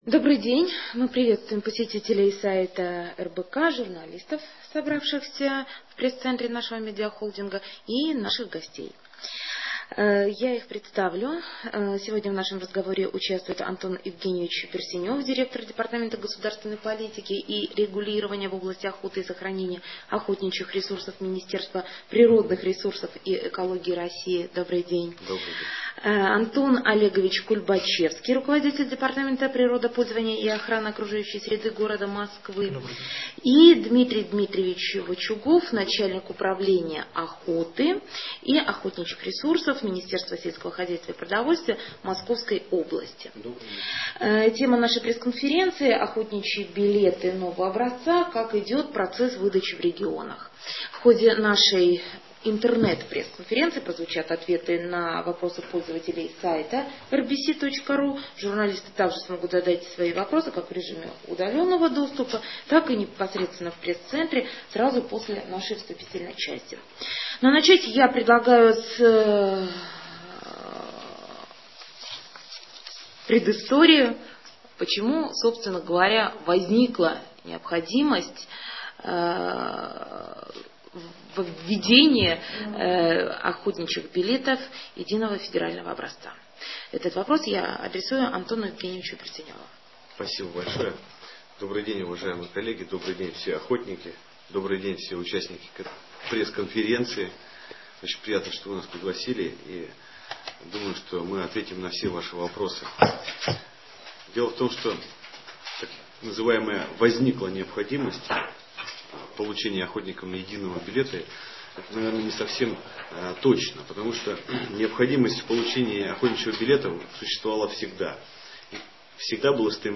В ходе пресс-конференции прозвучали ответы на вопросы интернет-аудитории и представителей СМИ.